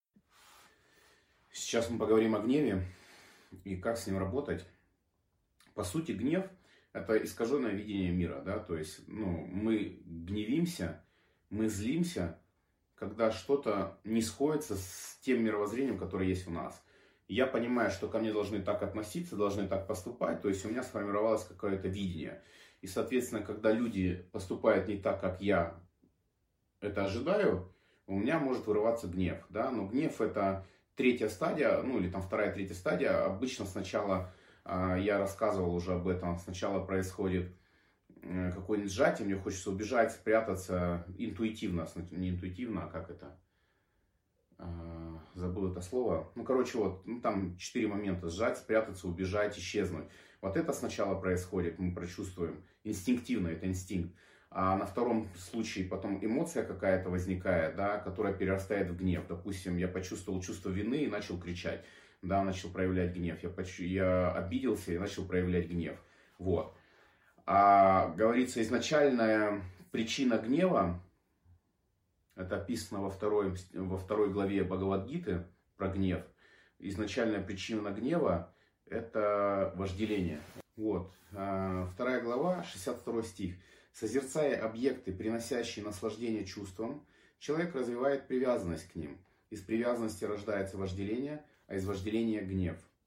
Аудиокнига Гнев и медитация. Режим дня и питание по биологическим часам человека | Библиотека аудиокниг